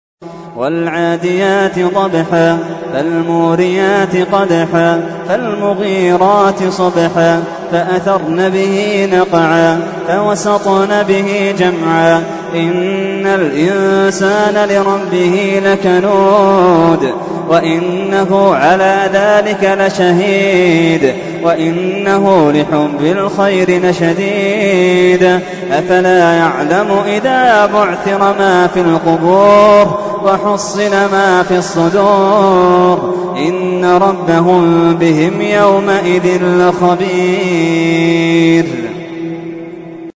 تحميل : 100. سورة العاديات / القارئ محمد اللحيدان / القرآن الكريم / موقع يا حسين